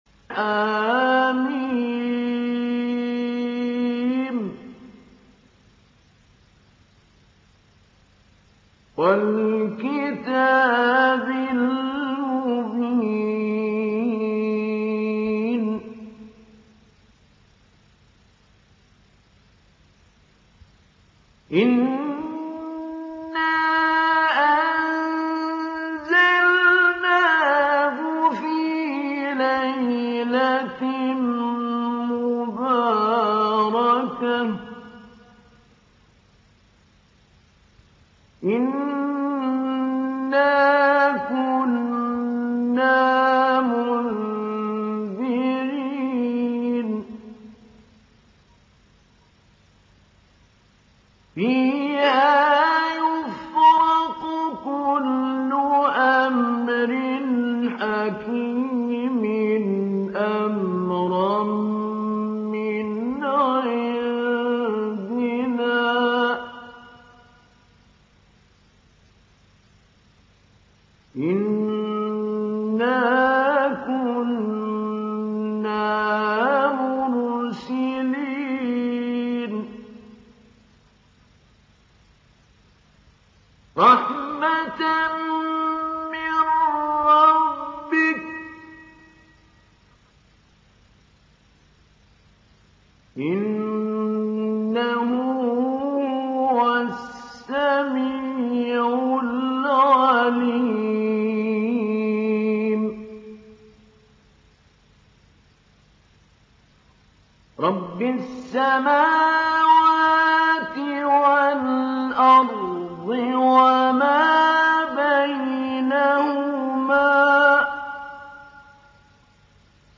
Download Surat Ad Dukhan Mahmoud Ali Albanna Mujawwad